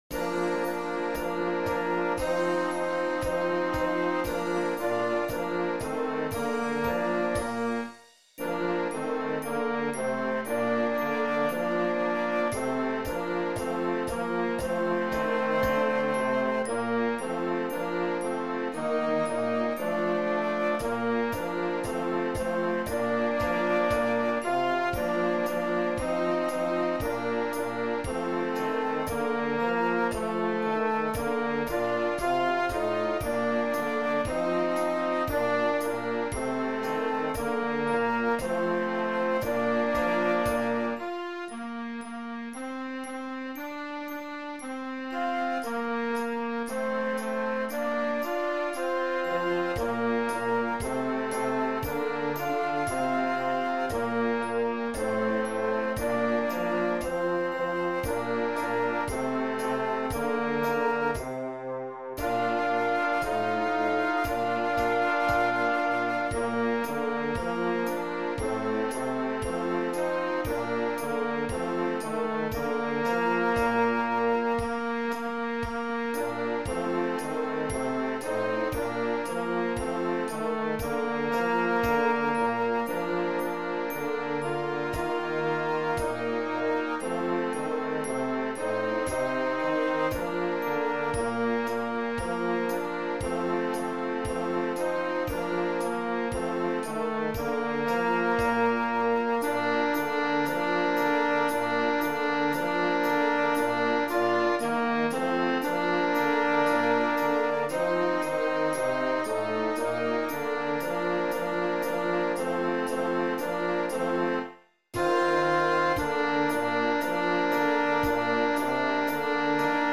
Beginner Concert Band
Computer MP3 file Score and parts.